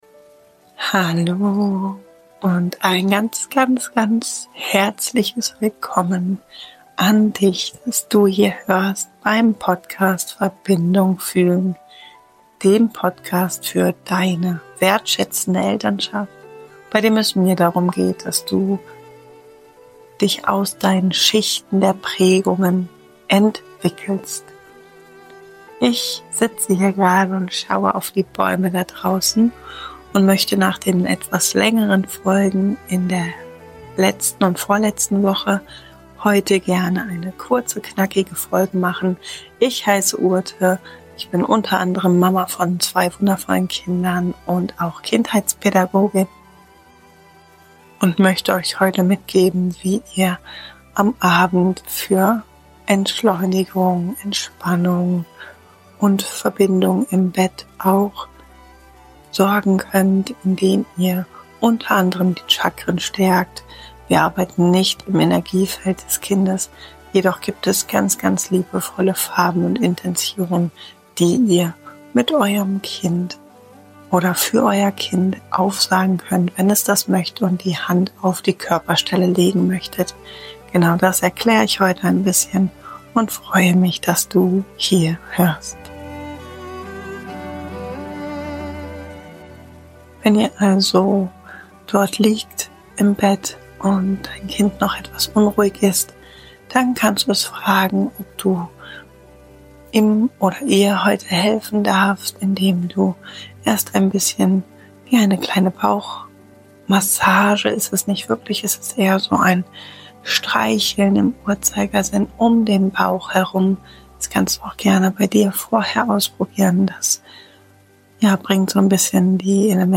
Diese geführte Reise durch die unteren vier Chakren hilft dir, nach einem vollen Tag wieder bei dir anzukommen.